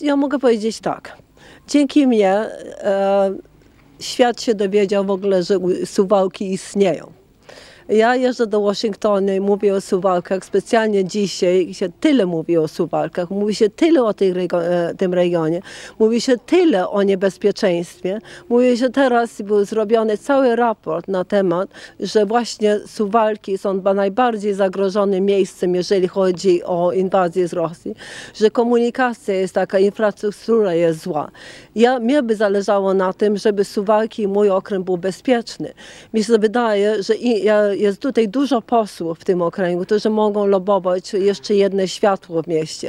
W piątek (17.08) przy okazji wizyty polonijnej młodzieży z Wielkiej Brytanii w wigierskim klasztorze, Anna Maria Anders udzieliła krótkiego wywiadu Radiu 5, w którym odniosła się do stawianych jej zarzutów.